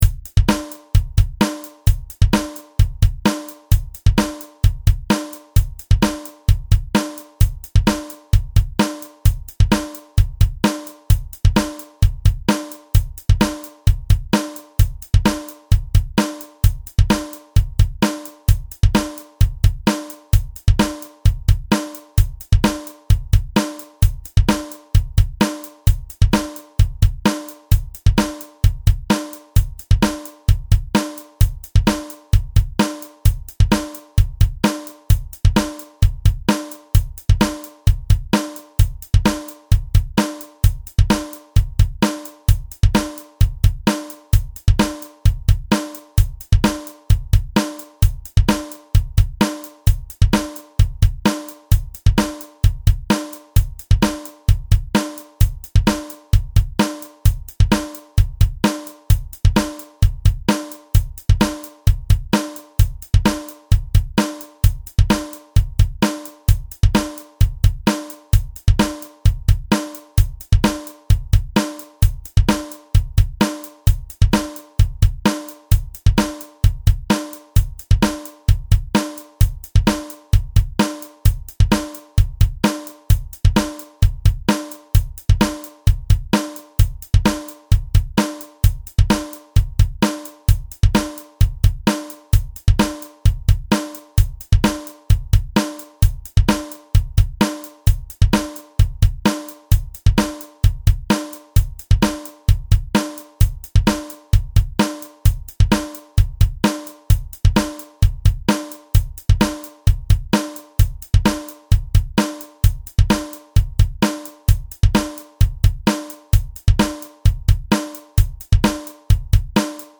The chart above shows you the drum beat.